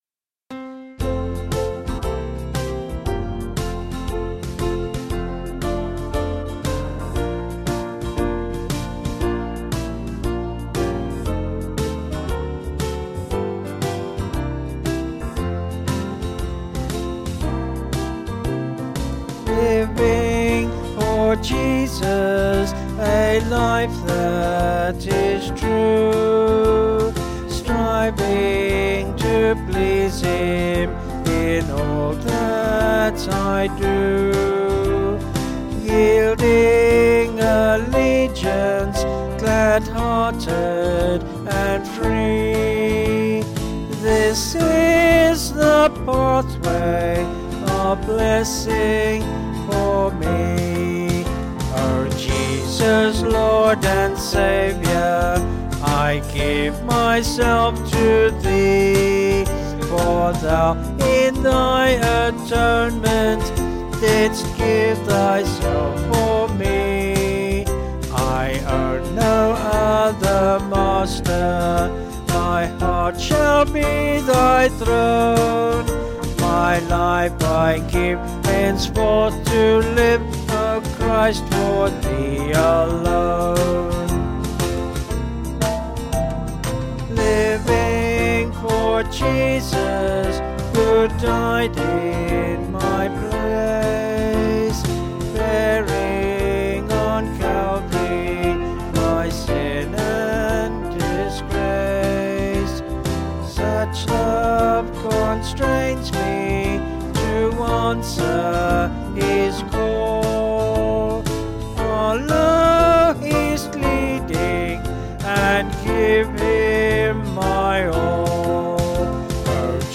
Vocals and Band   265.7kb Sung Lyrics